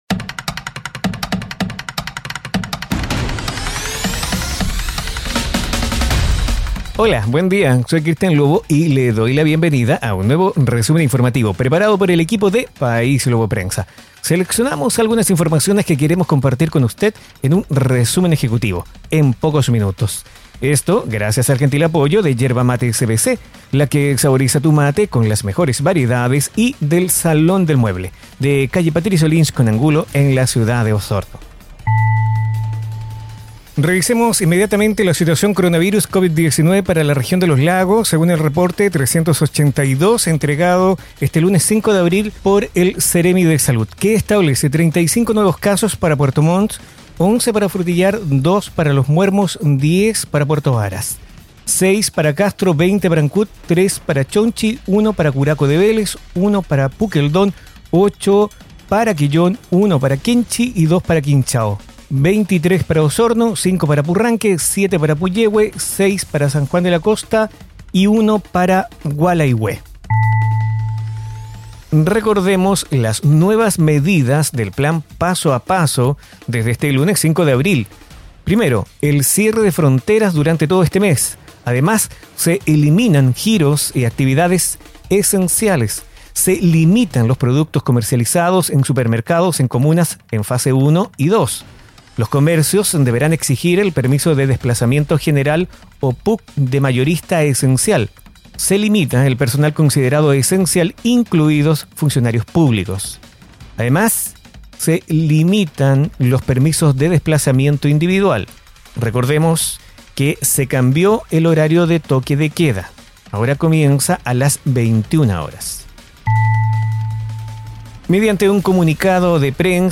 Informaciones enfocadas en la Región de Los Lagos. Difundido en radios asociadas.